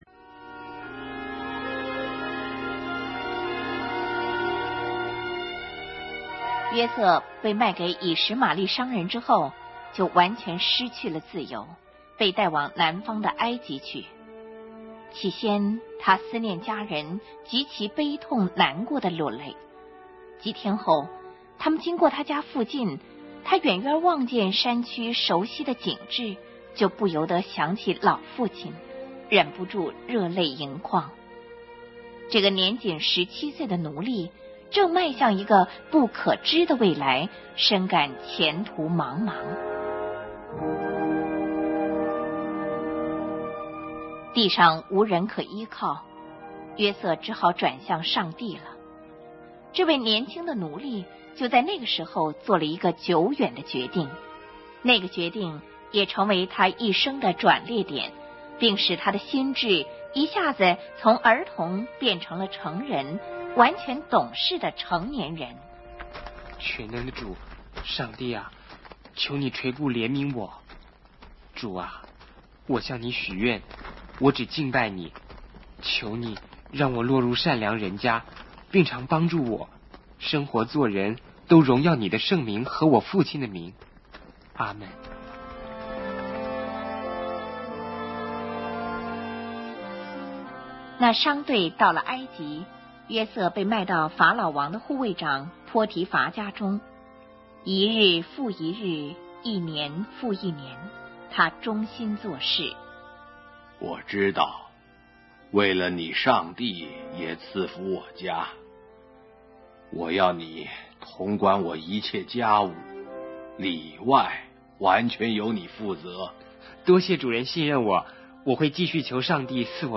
圣经故事广播剧
共270集，其中《旧约》故事 150集，《新约》故事 120集；全部为标准国语的MP3。